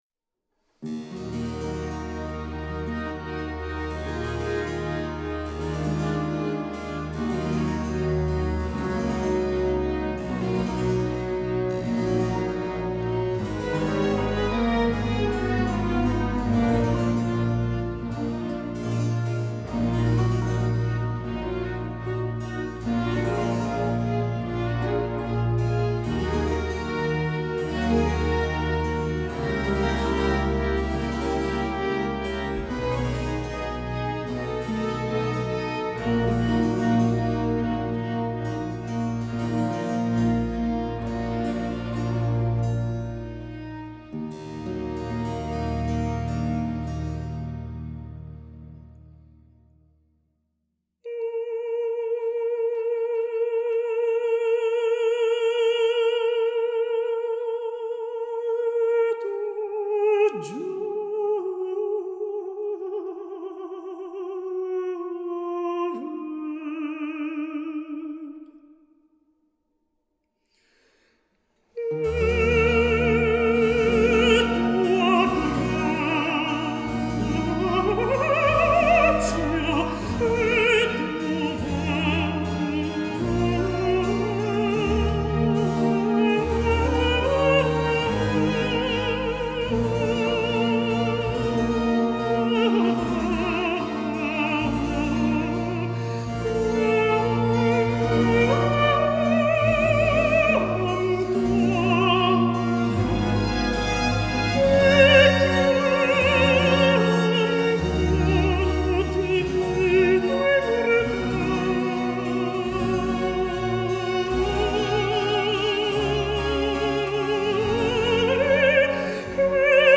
contre-ténor